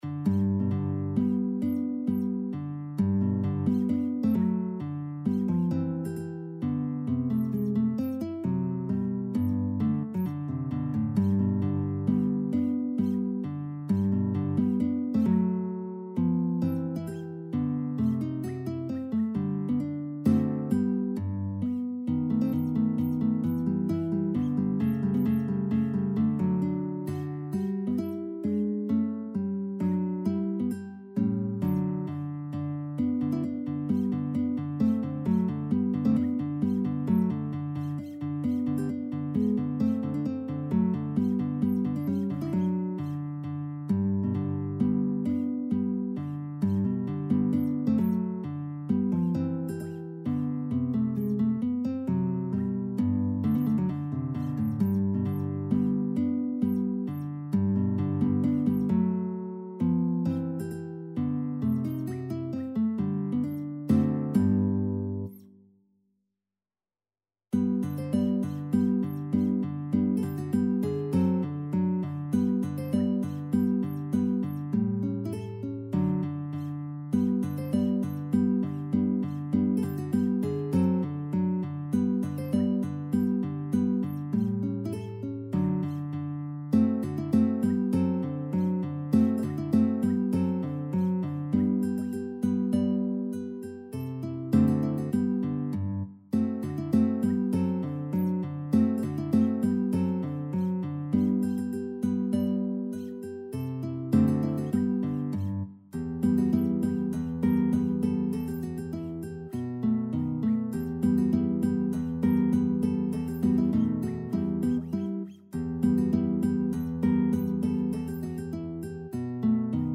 Instrument: Guitar
Style: Classical